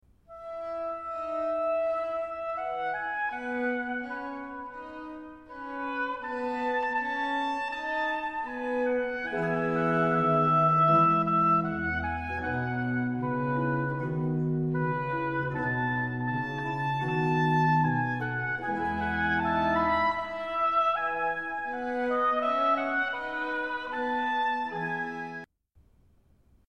Classical
RealAudio"Largo" Concerto in C major, RV 560. for two oboes, two clarinets and strings.